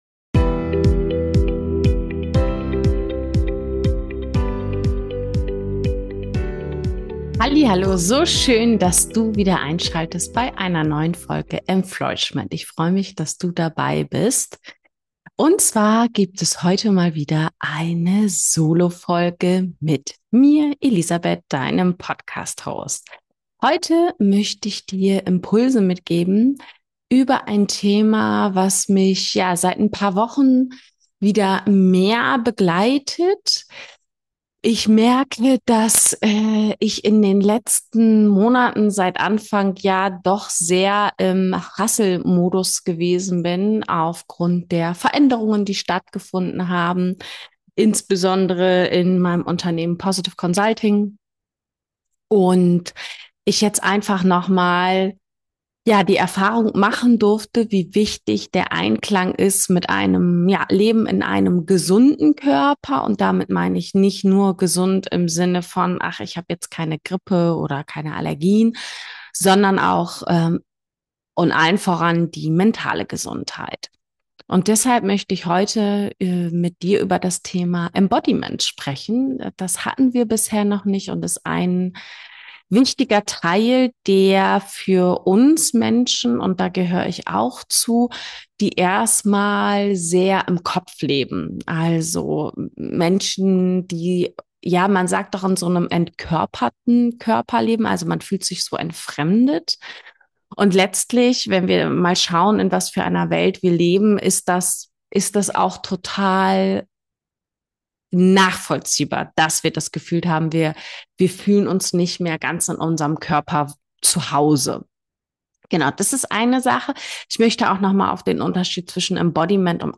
Heute habe ich eine Solofolge für dich aufgenommen über das Thema Embodiment. In dieser Folge tauchen wir tief in das Thema Embodiment ein – die bewusste Verbindung von Körper, Geist und Emotion.